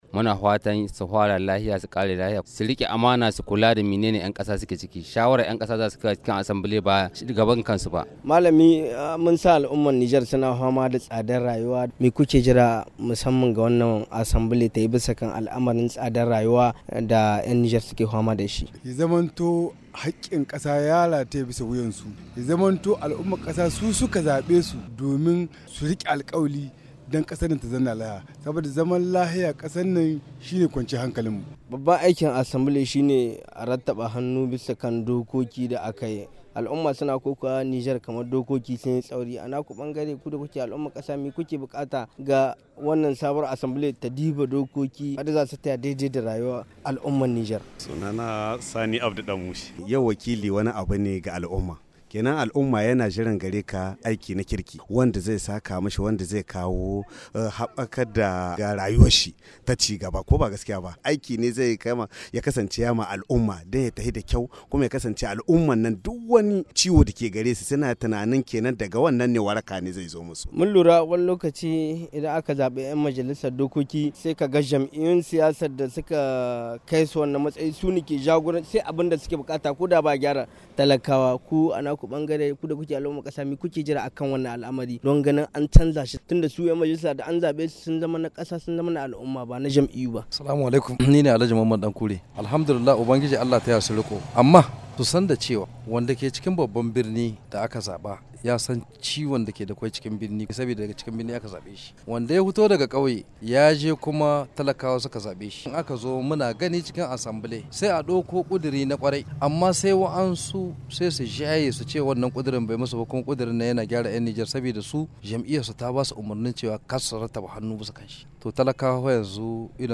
Wasu daga cikin mutanen Nijar na yi kira ga sabuwar majalisar da aka zaba a kasar da ta yi aikin da ya kawo ta, na bunkasa harkokin ci gaban kasa da al'ummarta, kamar yadda za a ji a cikin wannan rahoto.